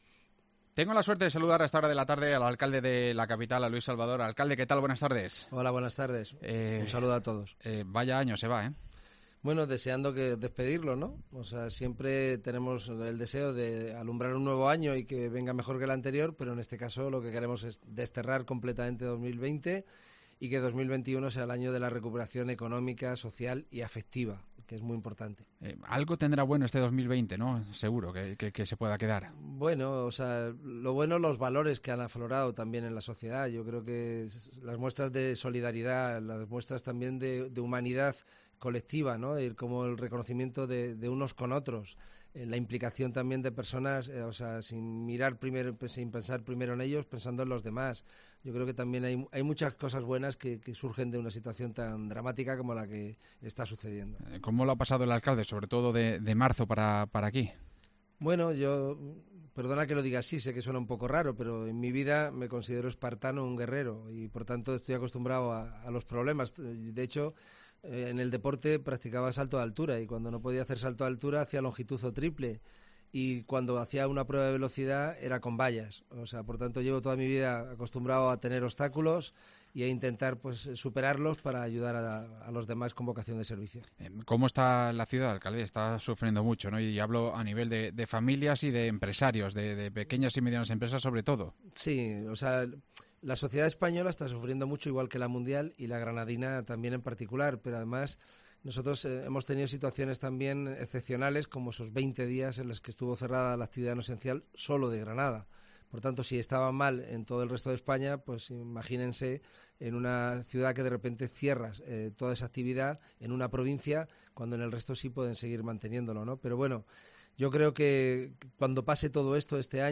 AUDIO: Escucha la entrevista de balance de año